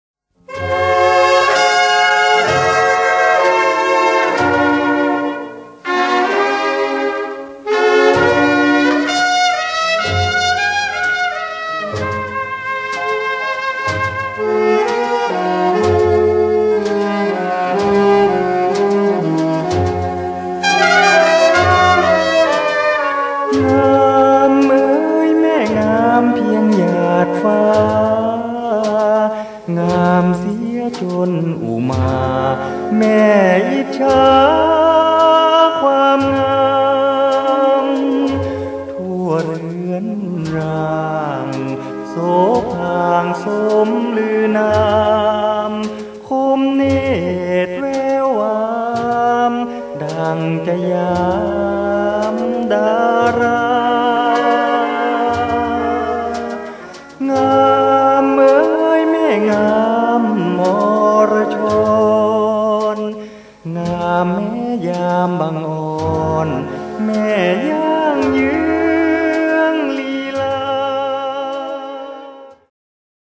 USB MP3 เพลงต้นฉบับเดิม